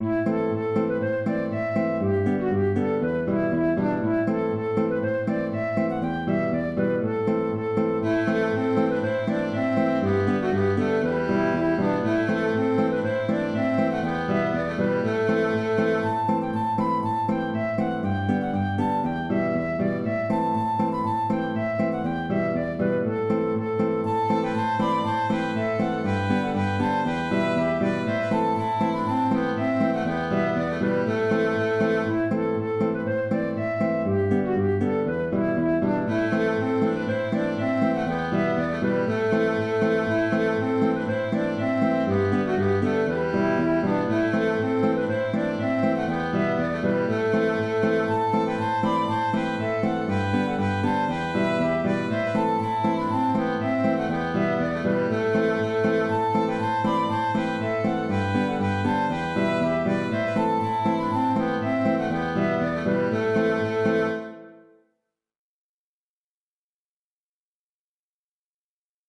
Balleydesmond (Polka) - Musique irlandaise et écossaise
Cette polka irlandaise en mineure est assez simple, et elle s’enchaîne facilement à d’autres polkas (en mineure ou majeure).
Auteur : Trad. Irlande.